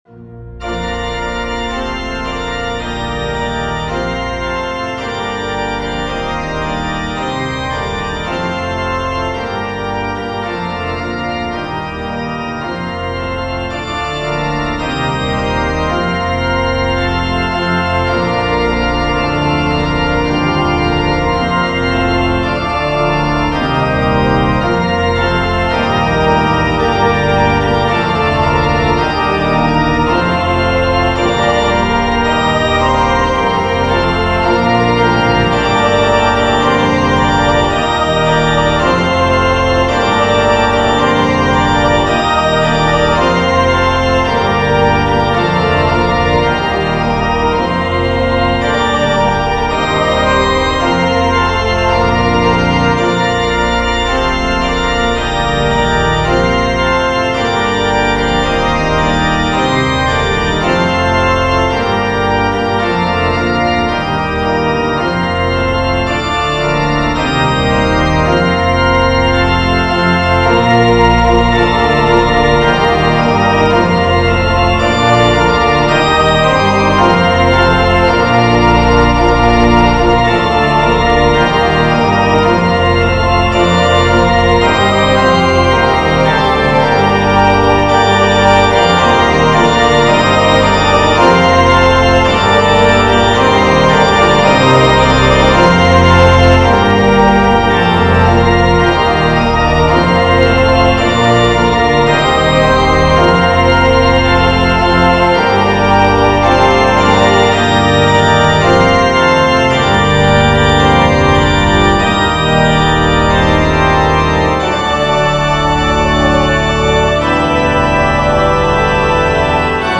Voicing/Instrumentation: SSATB , Organ/Organ Accompaniment